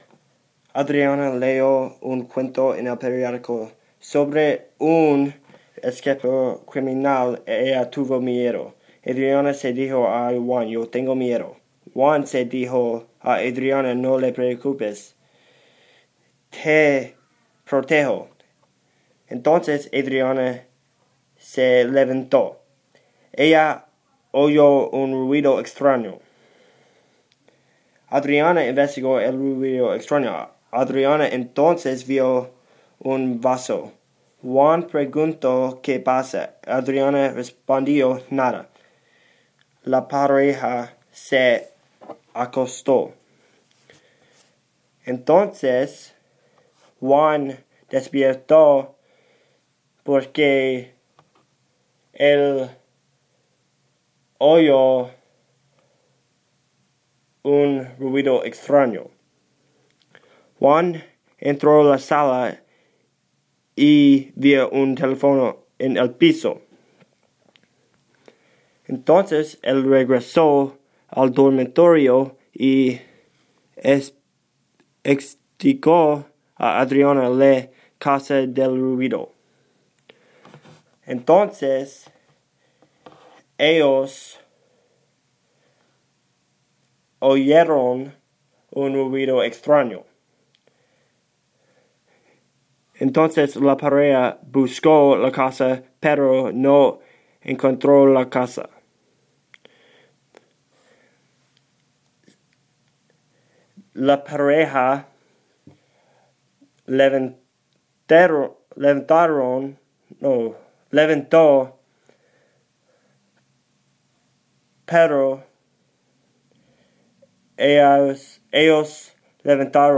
Ruido en la noche recording